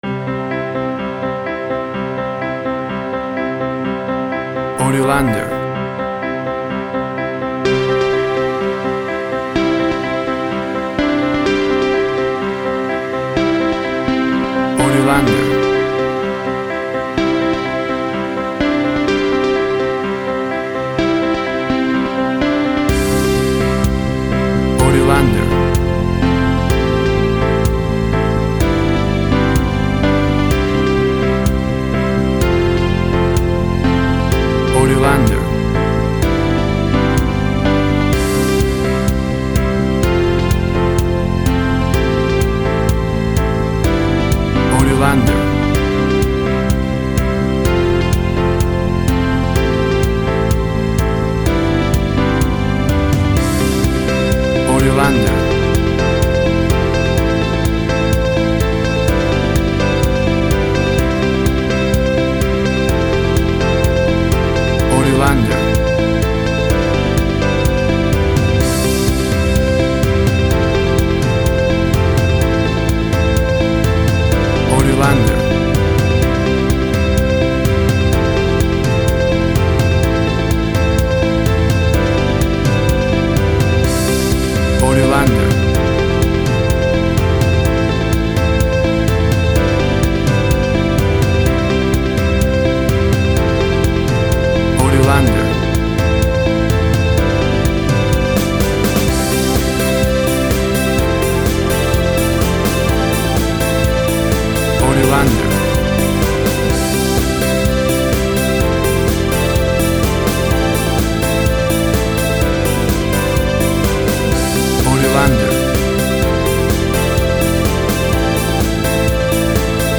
WAV Sample Rate 16-Bit Stereo, 44.1 kHz
Tempo (BPM) 126